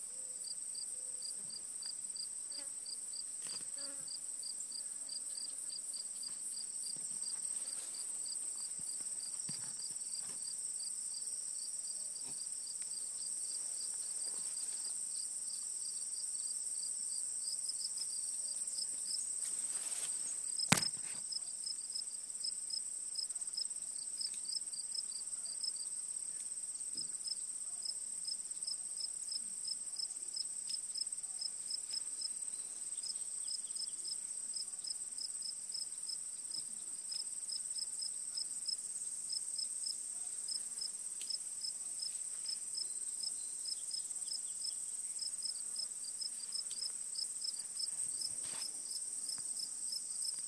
Crickets in Vermont
photo5While admiring the view and the peace and quiet of southwest Vermont I began to hear crickets.
In most cases it is the male that is doing the singing.  The male cricket rubs a sharp ridge on its wing against a series of files (think wrinkles) on its other wing.
sounds-of-vermont-crickets.m4a